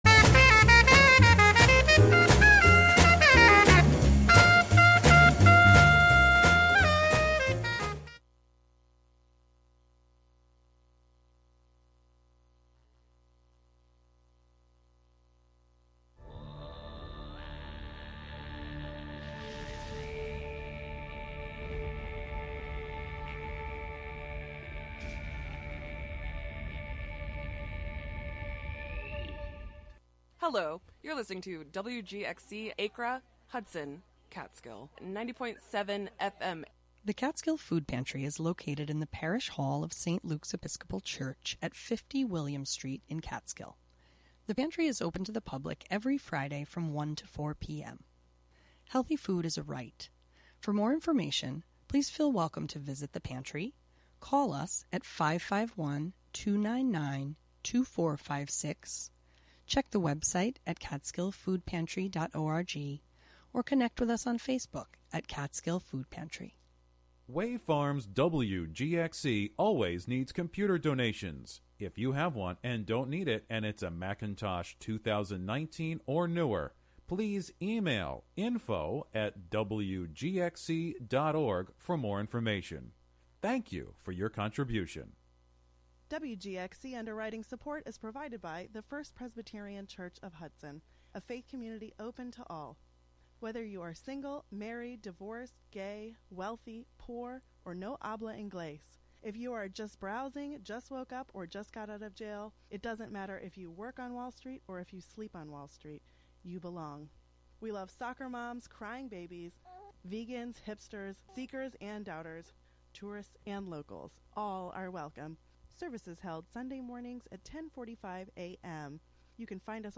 Monthly excursions into music, soundscape, audio document, and spoken word, inspired by the wide world of performance. Live from Ulster County.